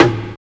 108 TOM MD-R.wav